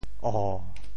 “哦”字用潮州话怎么说？